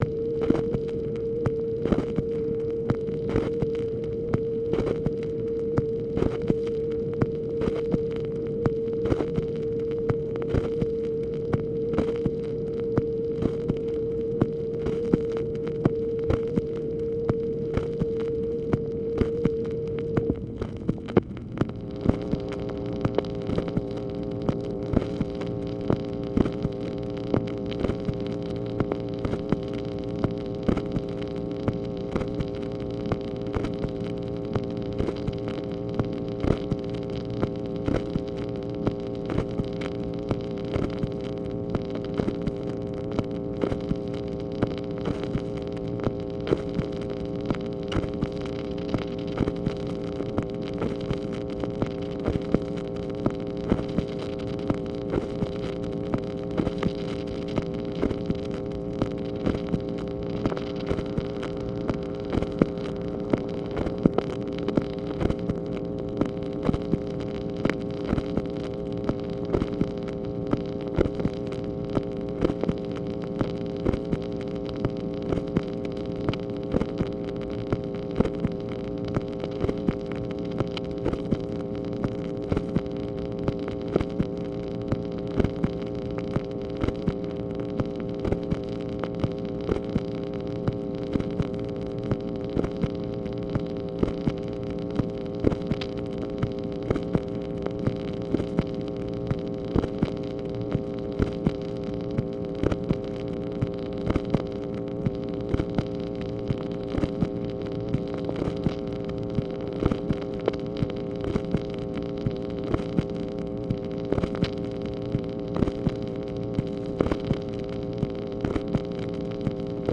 MACHINE NOISE, February 7, 1965
Secret White House Tapes | Lyndon B. Johnson Presidency